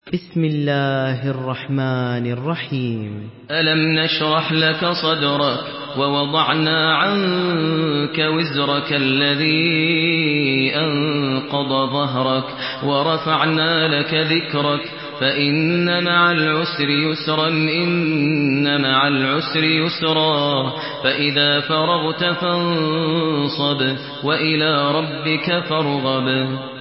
Sourate Ash-Sharh MP3 à la voix de Maher Al Muaiqly par la narration Hafs
Une récitation touchante et belle des versets coraniques par la narration Hafs An Asim.
Murattal Hafs An Asim